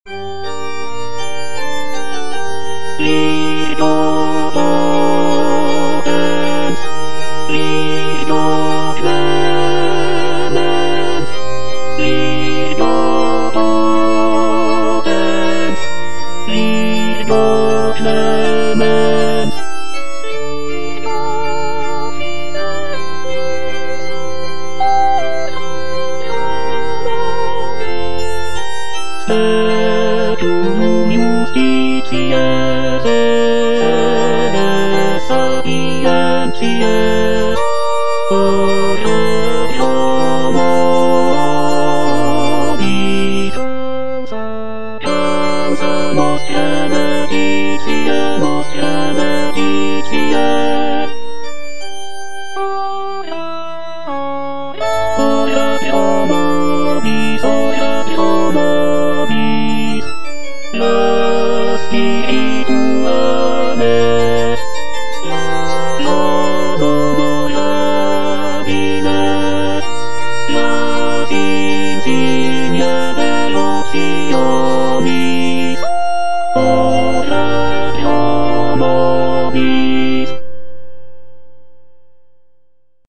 W.A. MOZART - LITANIAE LAURETANAE B.M.V. KV109 Virgo potens, virgo clemens - Tenor (Emphasised voice and other voices) Ads stop: auto-stop